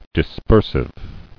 [dis·per·sive]